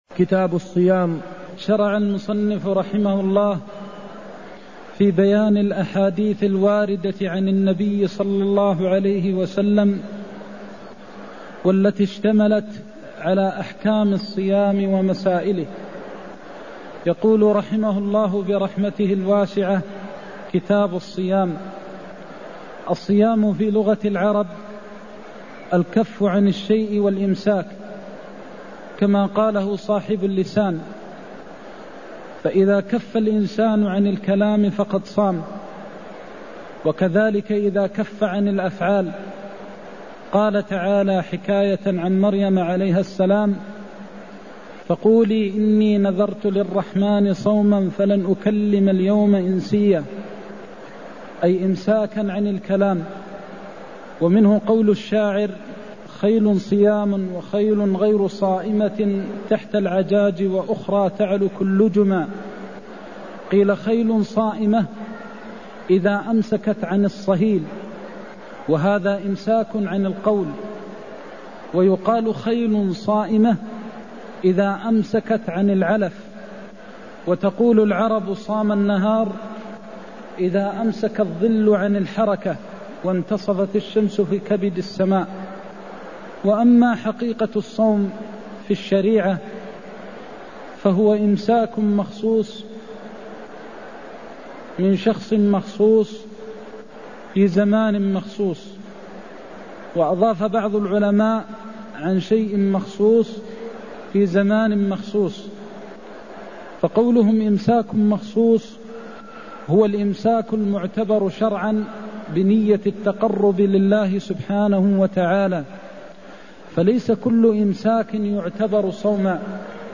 المكان: المسجد النبوي الشيخ: فضيلة الشيخ د. محمد بن محمد المختار فضيلة الشيخ د. محمد بن محمد المختار النهي عن صوم يوم أو يومين قبل رمضان (170)